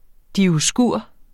dioskur substantiv, fælleskøn Bøjning -en, -er, -erne Udtale [ dioˈsguˀɐ̯ ] Oprindelse fra græsk Dioskouroi 'Zeus' sønner', dvs.